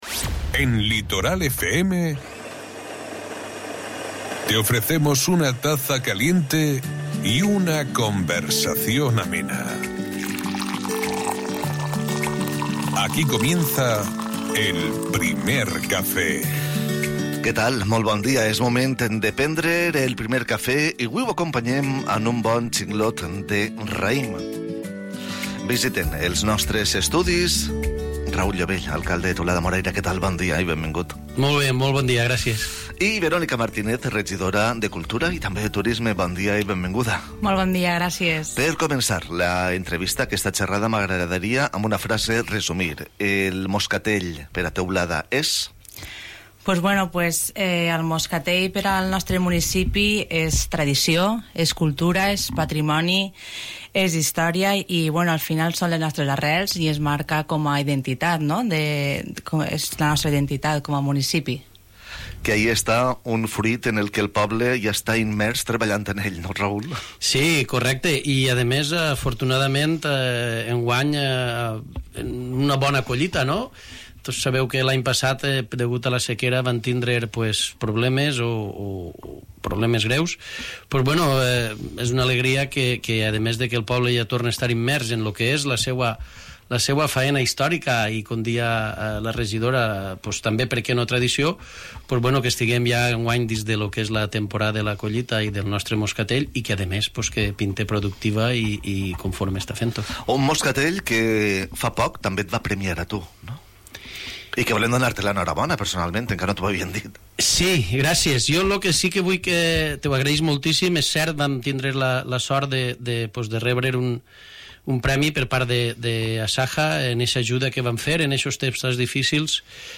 En nuestros estudios hemos tenido el placer de recibir a la concejala de Cultura y Turismo, Verónica Martínez, y al alcalde Raúl Llobell, quienes nos han compartido los detalles de esta edición tan significativa.